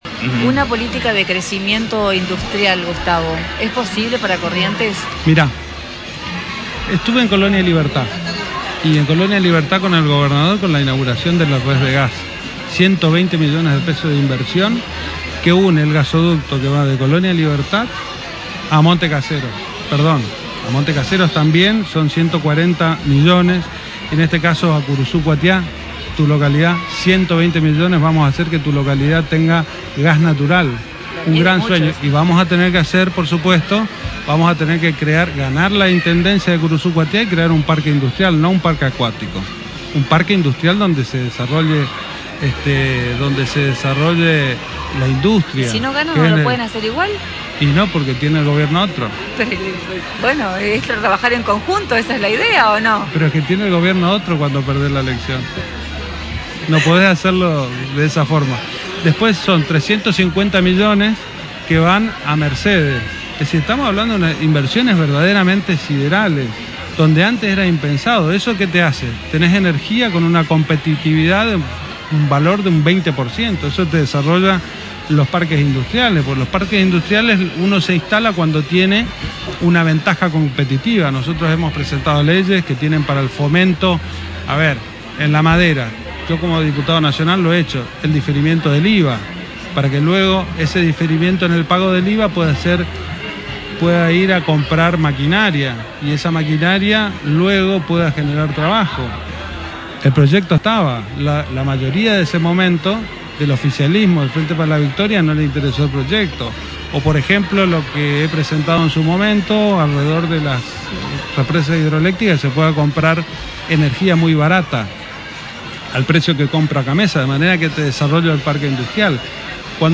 (Audio) Esas fueron las aseveraciones del candidato a gobernador por ECO+Cambiemos Gustavo Valdés en declaraciones radiales. El pasado viernes, en el marco de una visita al stand de Sudamericana en la Feria del Libro, el candidato de ECO+Cambiemos Gustavo Valdés recordó su paso el pasado lunes por Curuzú y remarcó sobre la importancia de la radicación de industrias, inversiones y establecer un Parque Industrial en nuestra ciudad.
En una extensa entrevista